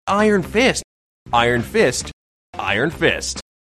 If so, we get Iron FIST:
iron-FIST-late-stress.mp3